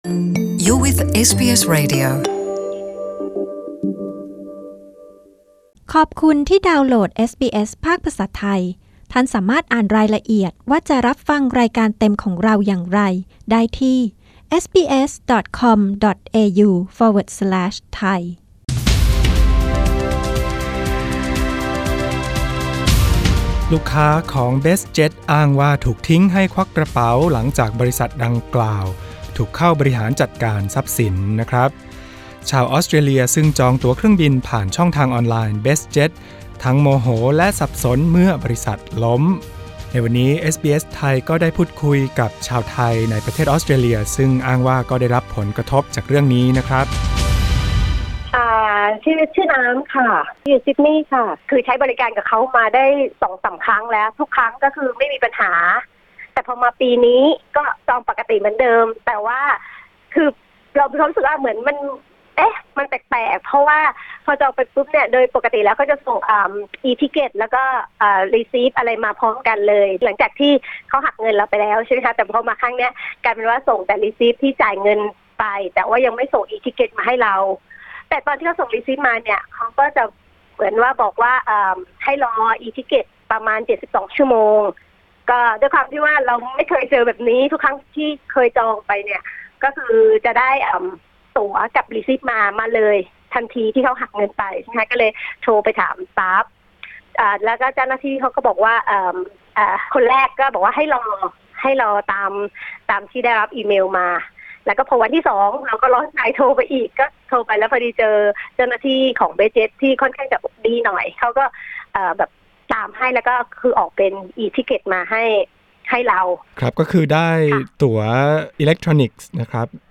กดปุ่ม (▶) เพื่อฟังสัมภาษณ์ชาวไทยผู้ที่อ้างว่าได้รับผลกระทบ/Audio in Thai language Source: Source: Getty